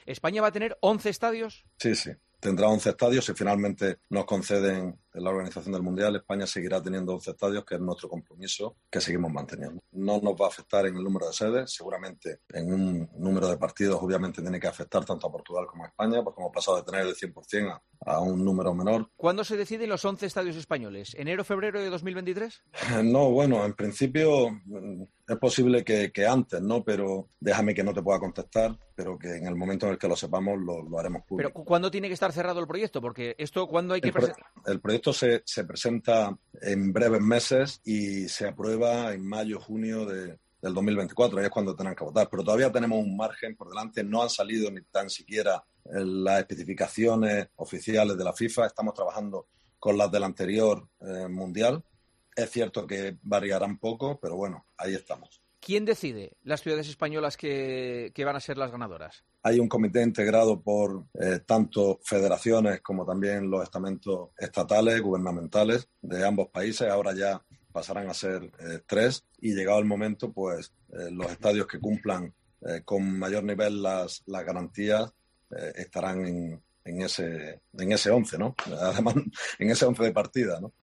El presidente de la Real Federación Española de Fútbol (RFEF), Luis Rubiales, atendió anoche a Juanma Castaño en El Partidazo de COPE y ofreció algunos detalles fundamentales del proceso en el que está el Nuevo Mestalla para ser sede del Mundial 2030.